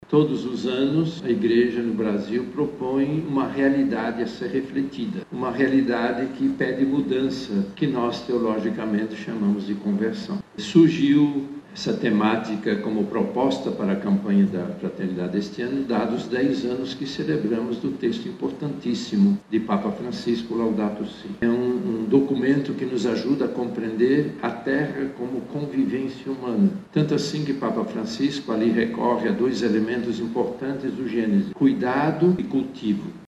O evento foi realizado no plenário da Câmara Municipal de Manaus nesta quinta-feira, 27 de março.
Em seu pronunciamento, o Cardeal Steiner recordou que a campanha não é uma novidade.